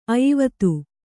♪ ayivatu